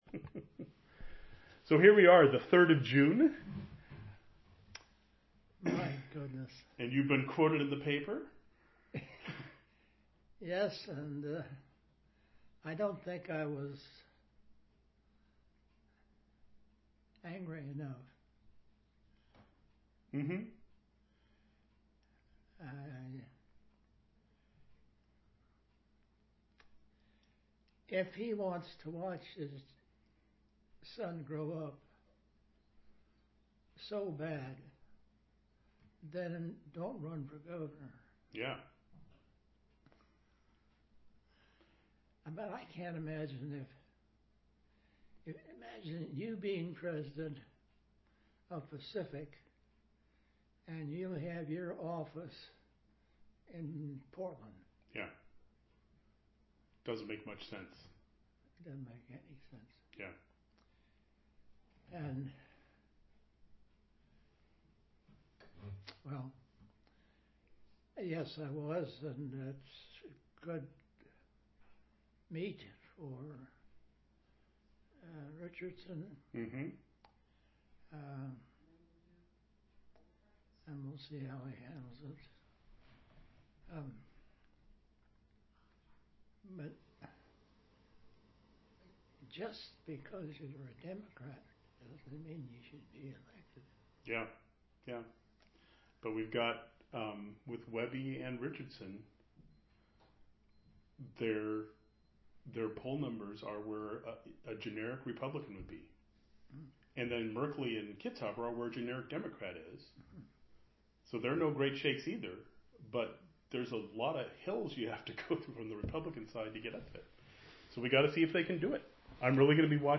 1db8daf23ecf5a80d726bbbbde1244c9a3d9a239.mp3 Title Governor Vic Atiyeh oral history interview, Jun 3, 2014 Description An interview of former Oregon Governor Victor Atiyeh, recorded on June 3, 2014.
His voice is relatively faint and thin compared to earlier recordings. The recording includes discussion of: the challenges of raising funds for political campaigns; the effectiveness of candidates like Richardson and Kitzhaber, highlighting their experience and the importance of government knowledge; the Recession of 1980-82 in Oregon; the importance of balancing budgets; the need for economic development; and reflections on the Rajneeshee crisis, emphasizing the role of law enforcement and the principles of democracy.